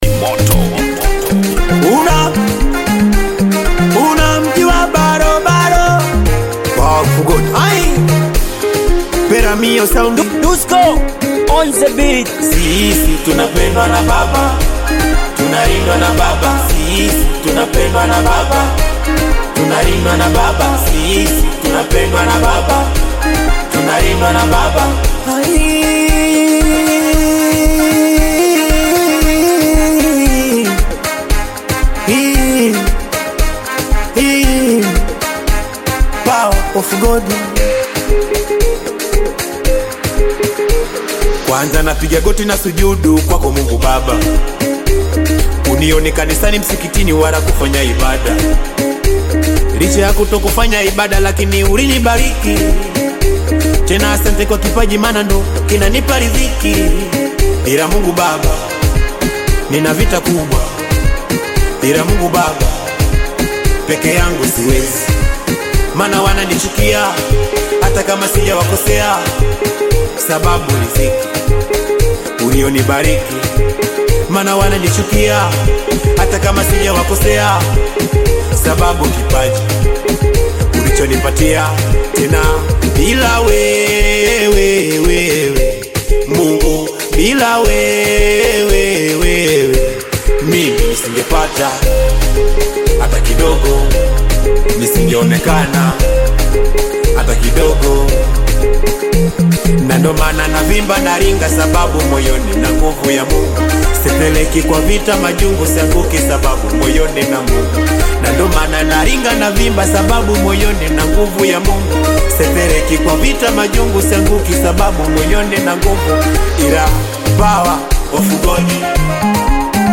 Singeli song